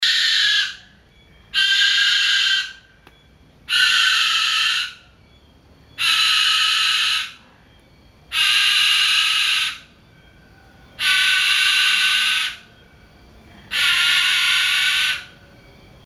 Now here is why I call these cute unique animals evil, they have a tendency to scream at about 160 decibels from 10pm to 5am sounding like someone is being murdered.
So I present for your listening displeasure my iPhone recording of the Hyrax at 2am.
Hyrax in Kenya at 2am.mp3